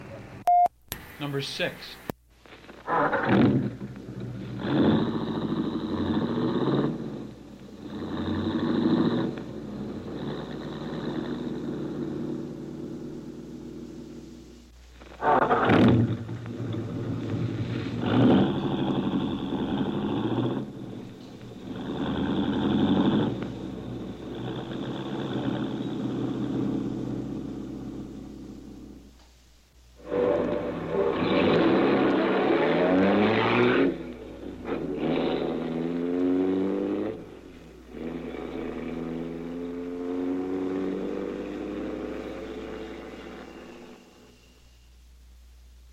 老式汽车 " G1307汽车车门和驱动装置
描述：两扇门关闭，汽车立即启动并驶离。一些轮胎尖叫，因为它缩小了。 这些是20世纪30年代和20世纪30年代原始硝酸盐光学好莱坞声音效果的高质量副本。 40年代，在20世纪70年代早期转移到全轨磁带。我已将它们数字化以便保存，但它们尚未恢复并且有一些噪音。
Tag: 汽车 运输 光学 经典